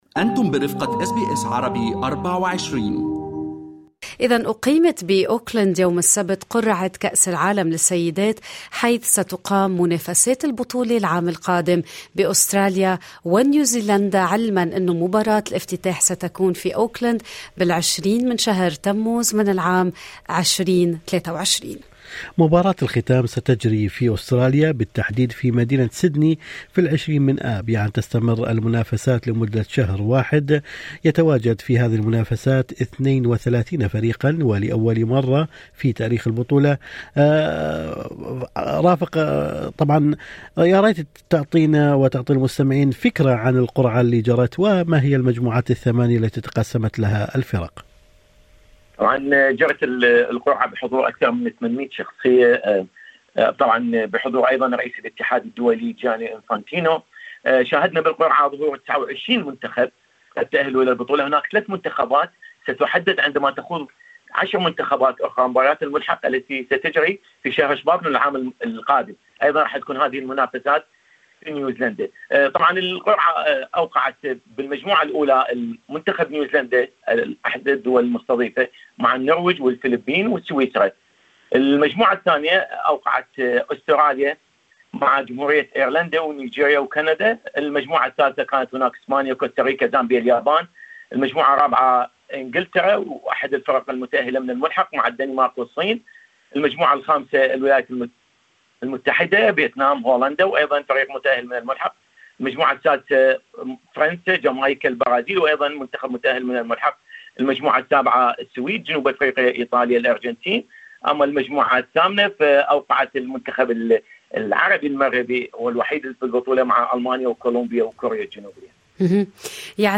في لقاء مع اس بي أس عربي24